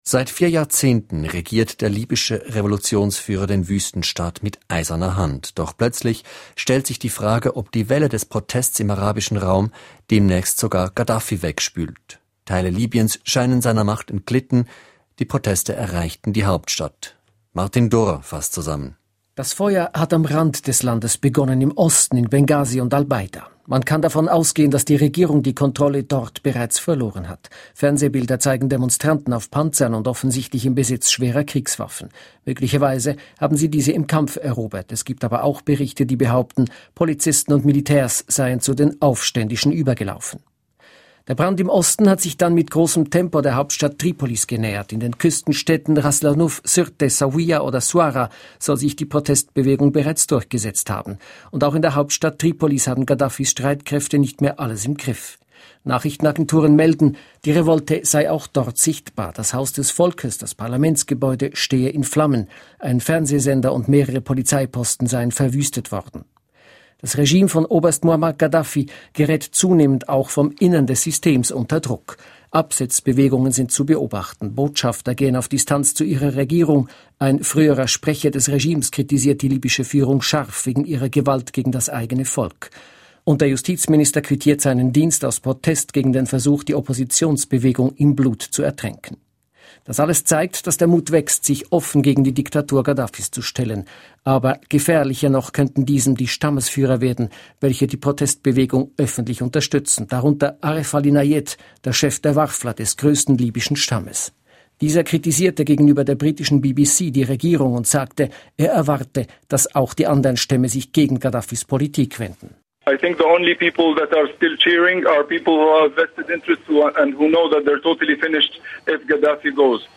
Seit die Proteste Libyens Hauptstadt Tripolis erreicht haben und niedergeschlagen wurden, wenden sich immer mehr Vertreter des Regimes von Ghadhafi ab. Ein Überblick und das Gespräch